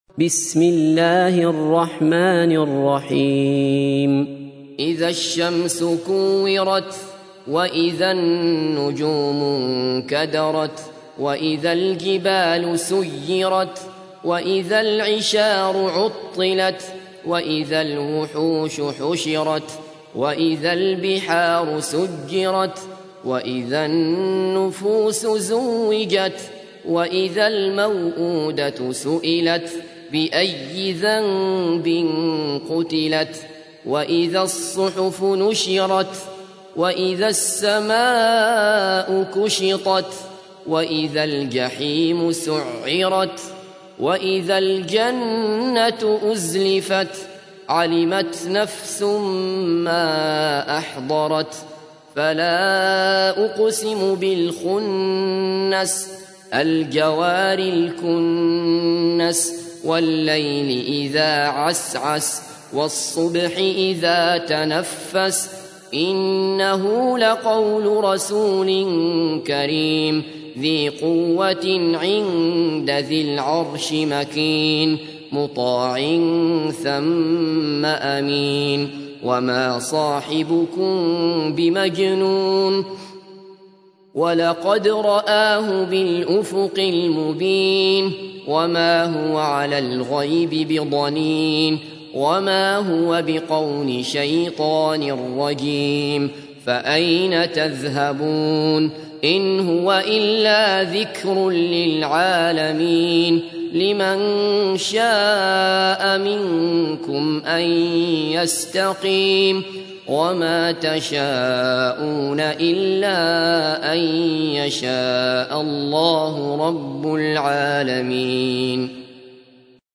تحميل : 81. سورة التكوير / القارئ عبد الله بصفر / القرآن الكريم / موقع يا حسين